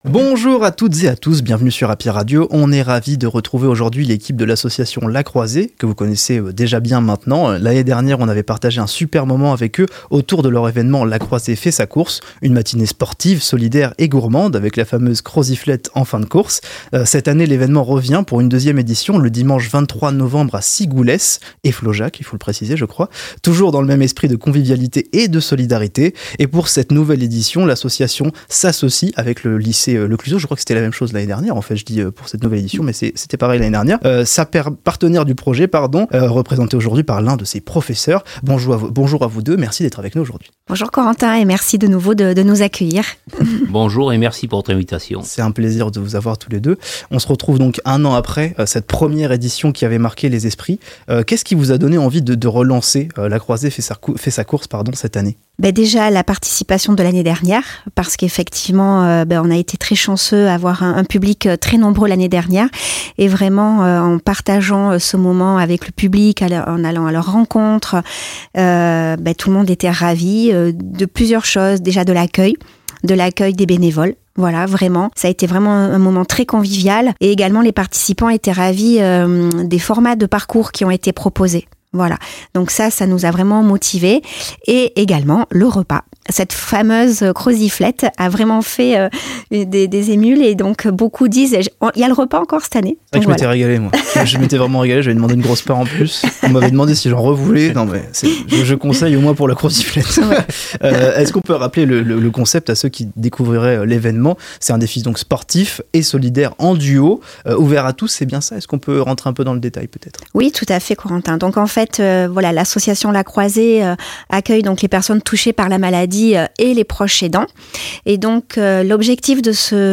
Interviews HappyRadio — La Croisée fait sa course